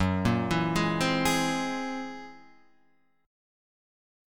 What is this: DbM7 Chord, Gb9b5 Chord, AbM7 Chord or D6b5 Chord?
Gb9b5 Chord